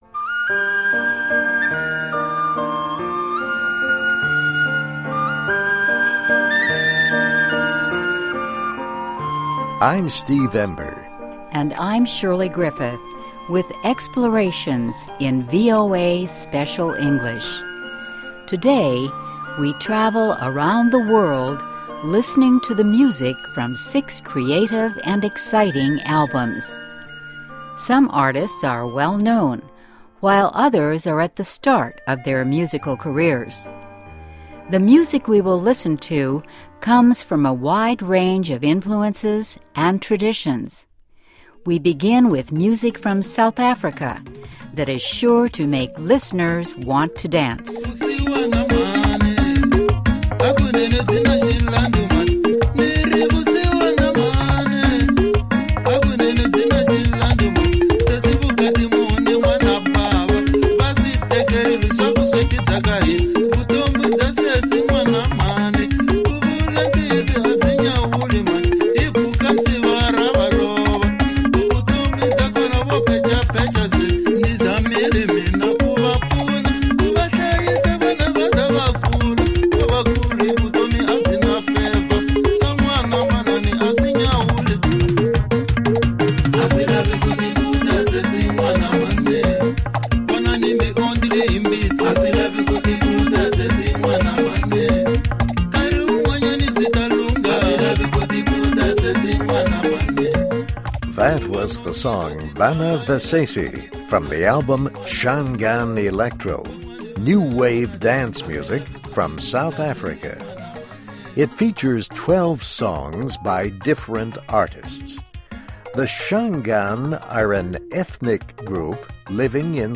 Music from six albums representing a wide range of influences and traditions | EXPLORATIONS